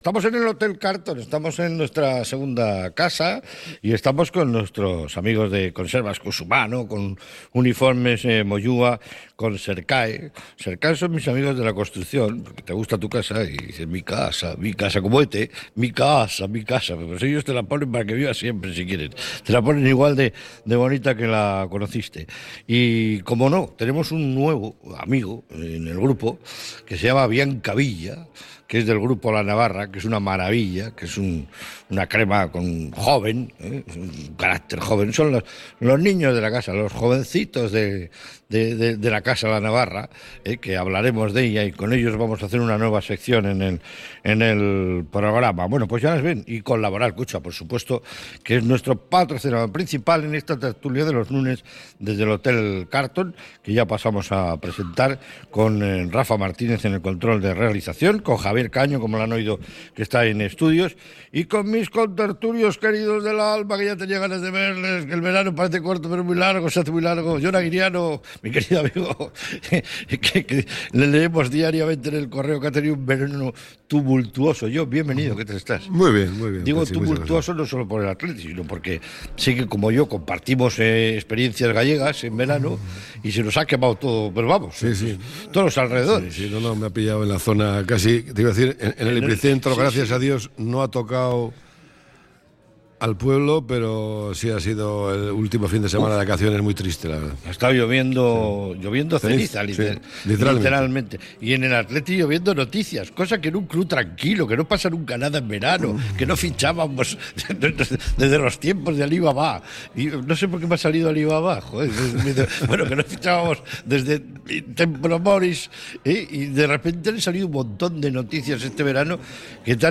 desde el Hotel Carlton